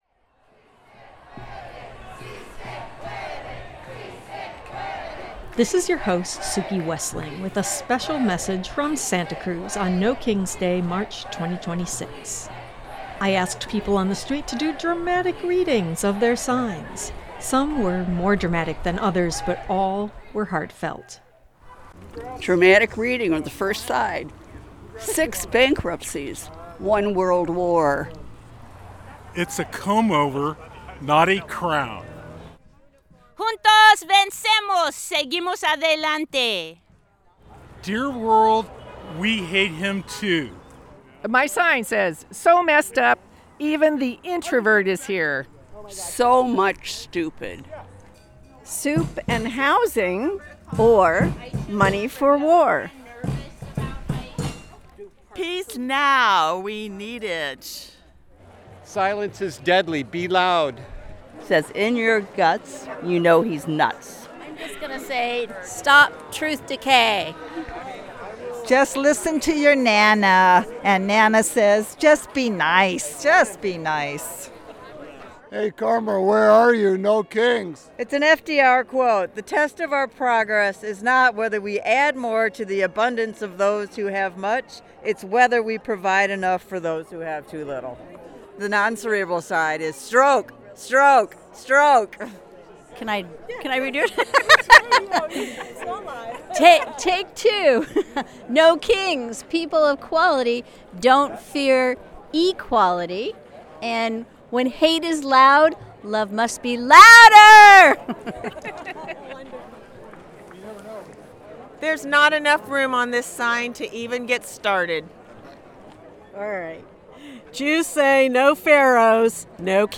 An Audio Montage I walked around my local No Kings 2026 Rally and asked people to do dramatic readings of their signs. Some are more dramatic than others, but all....